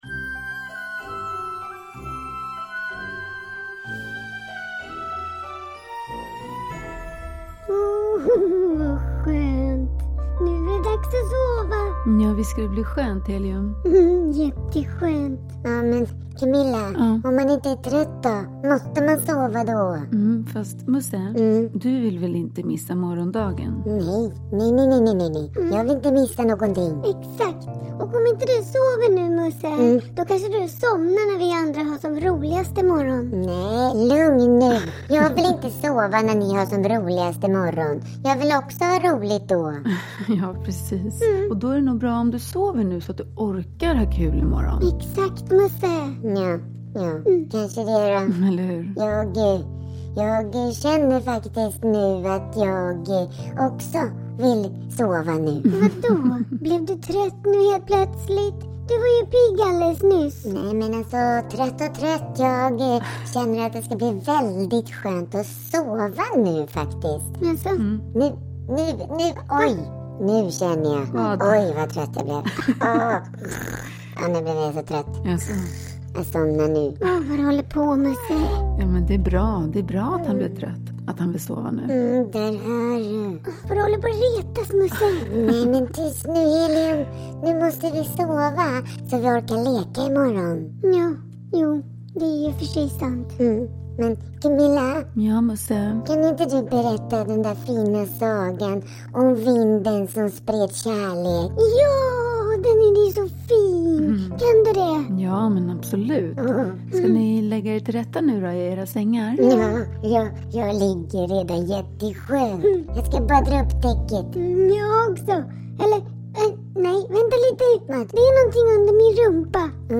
Musse & Helium. Kärlekens vind - en godnattsaga – Ljudbok
Med en sagoskimrande text och stora vackra ljudlandskap bjuds här in till en fantasivärld där små och stora lyssnare kan få hjälp att hitta lugnet.
Uppläsare: Camilla Brinck